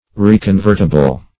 Search Result for " reconvertible" : The Collaborative International Dictionary of English v.0.48: Reconvertible \Re`con*vert"i*ble\ (r?`k?n*v?rt"?*b'l), a. (Chem.)